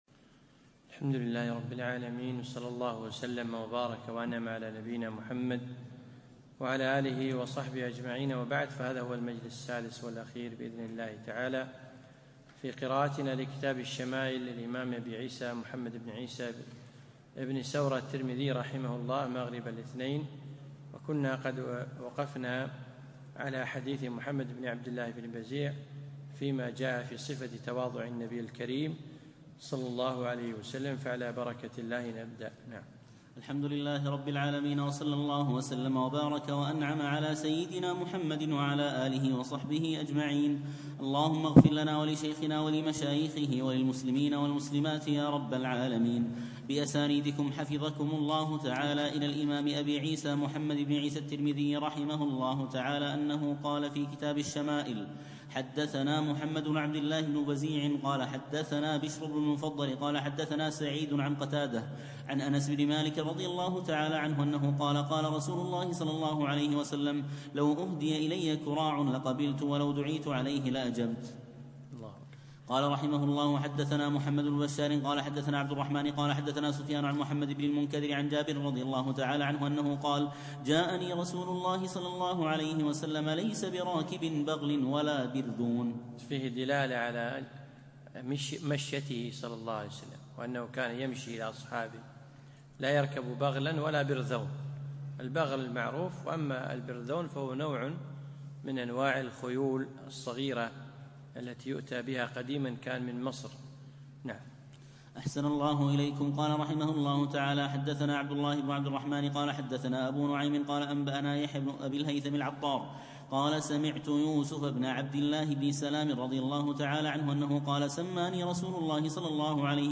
يوم الأثنين 22 ربيع الأخر 1437هـ الموافق 1 2 2016م في مسجد عائشة المحري المسايل